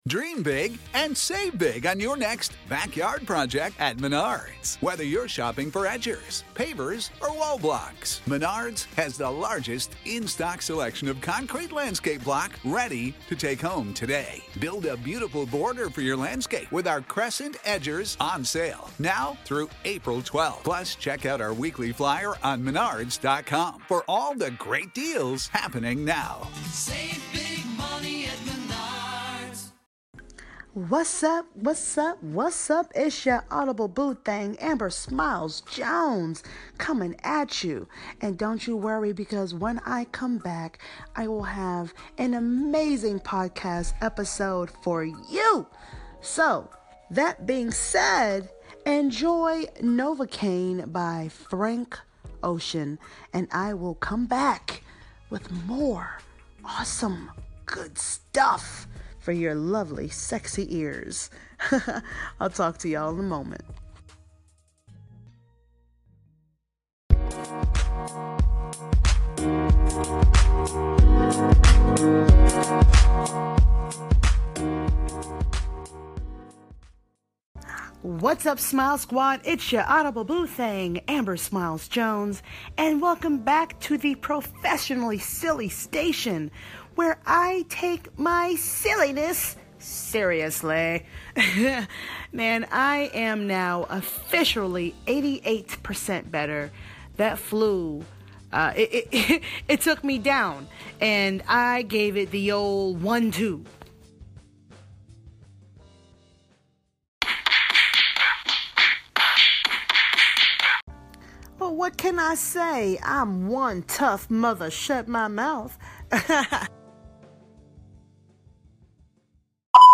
Margaritas, Call-in & Music🎧🙌🏾😎🗣
We had a few call-ins, the Anchor platform is all different and today is National Margarita Day!